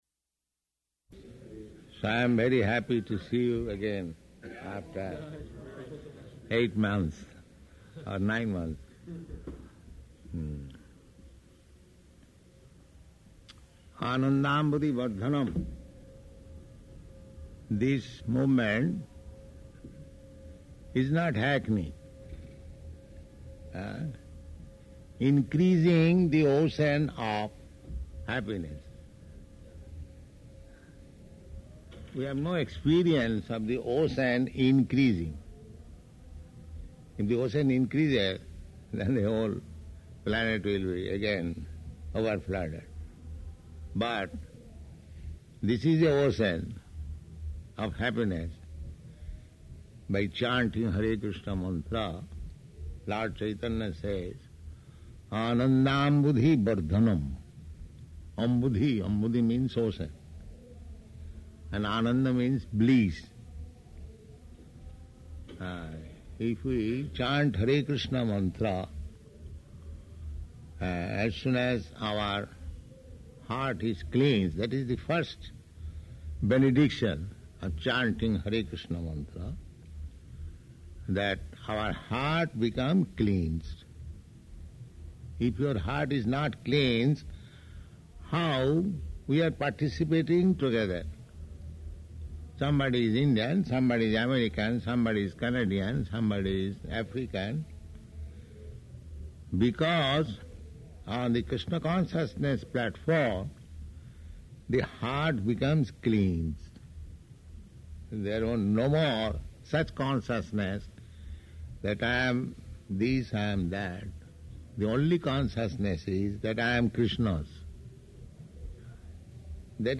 Arrival Lecture
Arrival Lecture --:-- --:-- Type: Lectures and Addresses Dated: June 29th 1971 Location: Los Angeles Audio file: 710629AR-LOS_ANGELES.mp3 Prabhupāda: So I am very happy to see you again after eight month, or nine month.